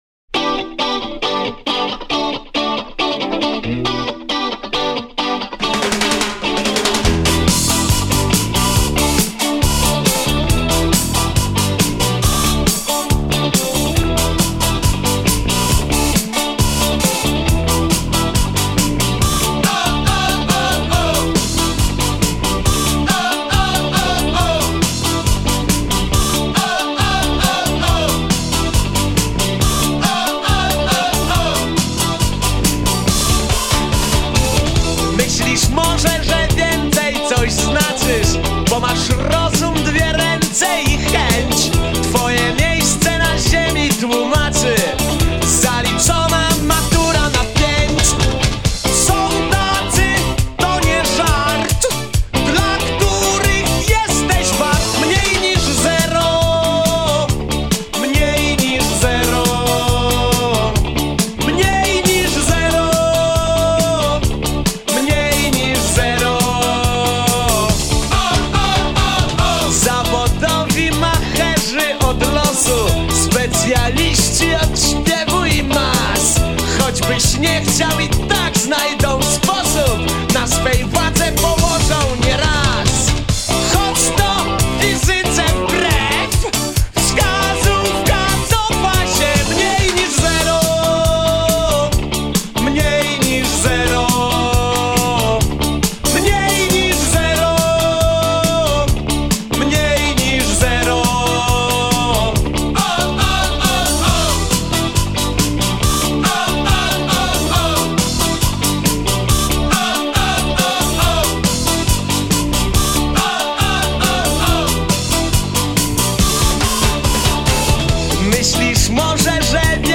popular Polish rock band
solo guitar
Paweł Mścisławski - bass guitar
Jarosław Szlagowski - drums